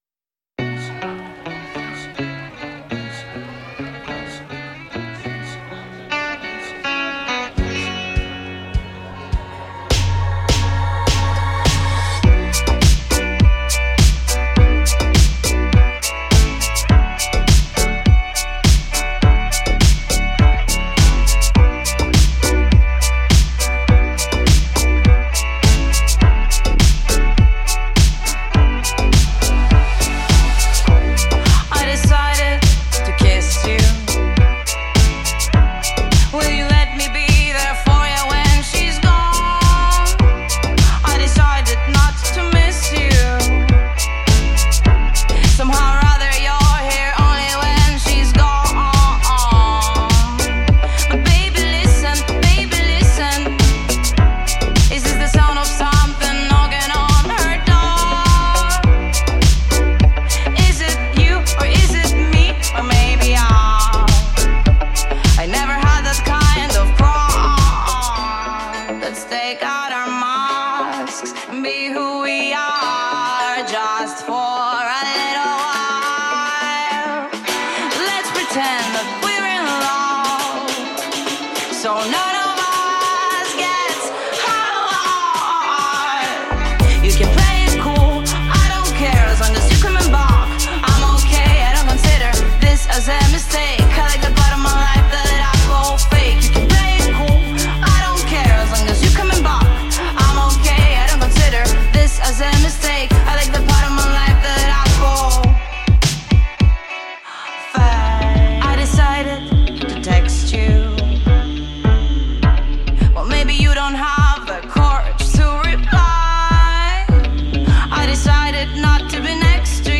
# Electronic # Dance # Dance Pop